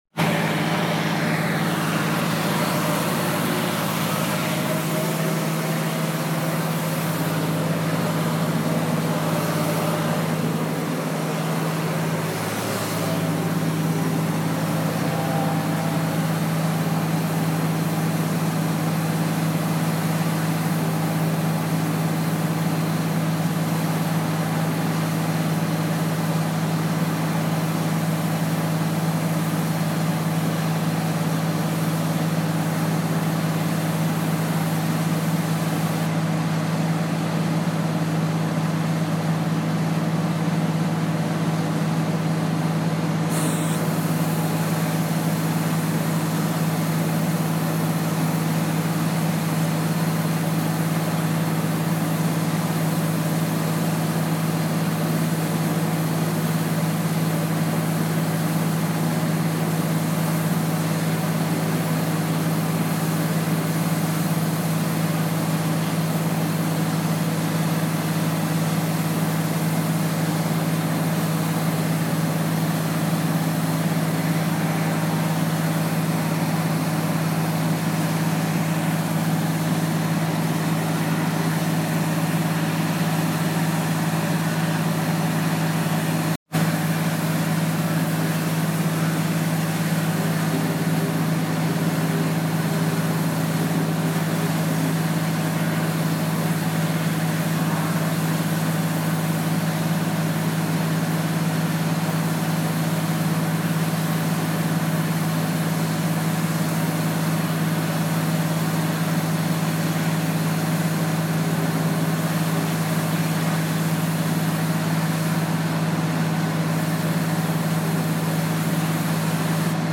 Water blasting and sucking dirt